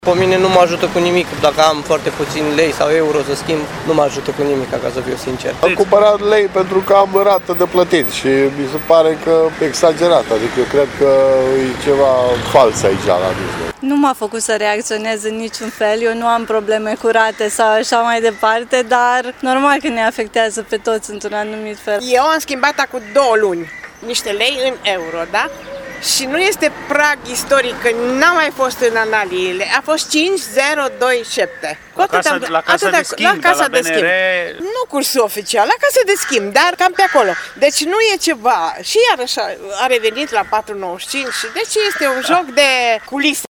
voxuri-curs-leu.mp3